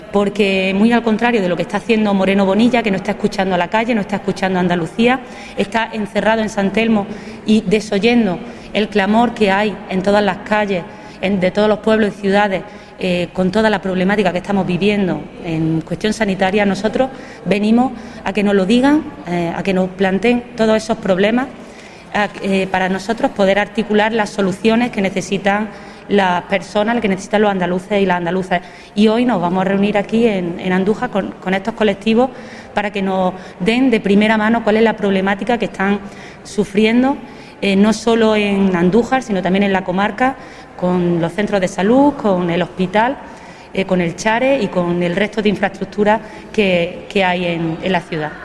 La parlamentaria socialista Mercedes Gámez advirtió hoy que la integración del Hospital de Andújar en el SAS “no ha supuesto ningún avance ni mejora ni para los pacientes ni para los profesionales”. Gámez hizo estas declaraciones antes de mantener una reunión con colectivos sanitarios en Andújar, dentro del proceso de escucha activa abierto por el PSOE-A.